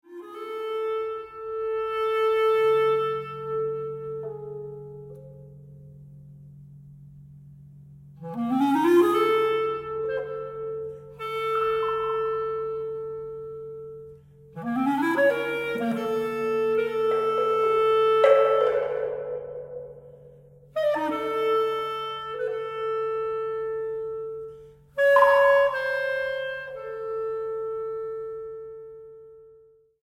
Música de Cámara